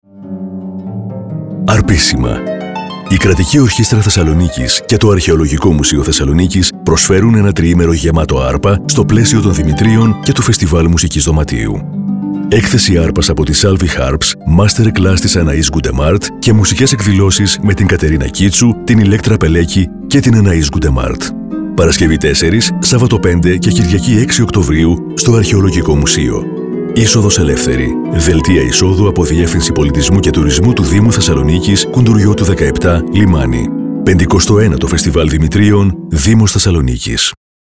Ραδιοφωνικό σποτ 4-6οκτ24_Arpissima.mp3